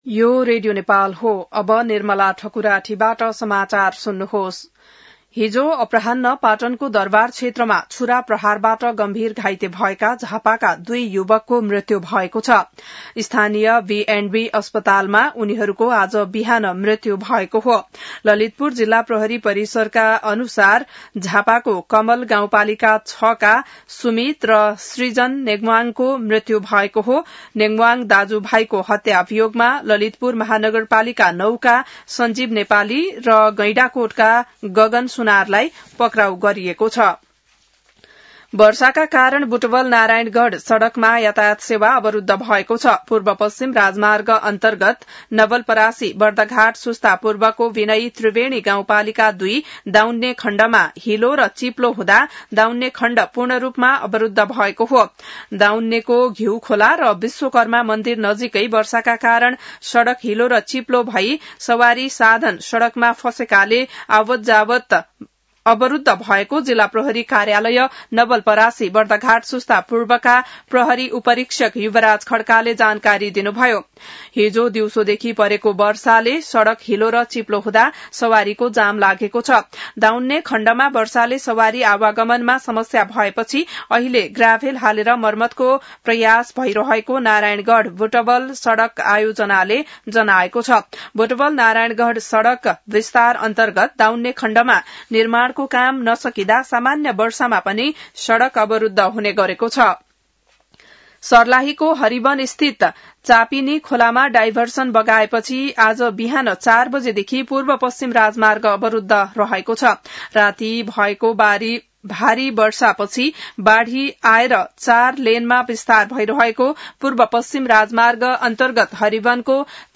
बिहान १० बजेको नेपाली समाचार : २६ चैत , २०८२